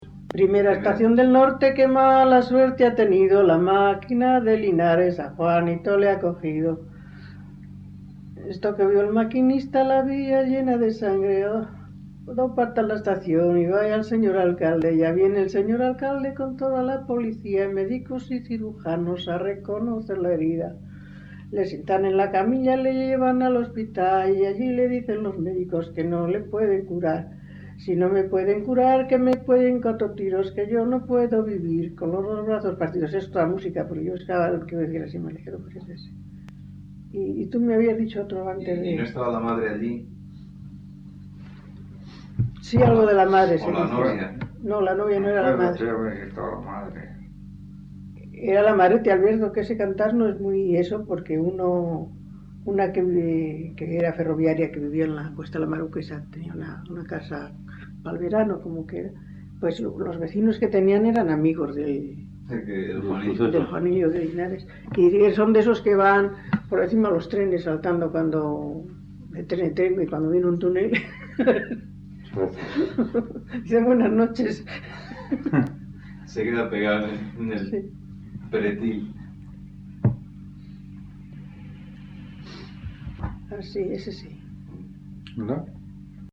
Grabación realizada en La Overuela (Valladolid), en 1977.
Género / forma: Canciones populares-Valladolid (Provincia) Icono con lupa